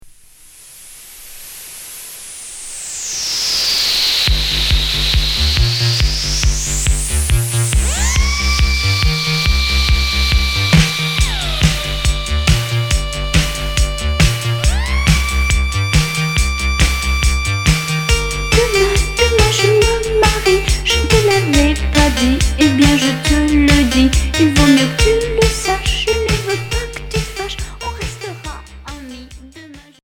New wave 45t promo en pochette générique uniquement .